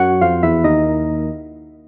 defeat.wav